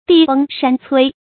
地崩山摧 注音： ㄉㄧˋ ㄅㄥ ㄕㄢ ㄘㄨㄟ 讀音讀法： 意思解釋： 土地崩裂，山嶺倒塌。多形容巨大變故。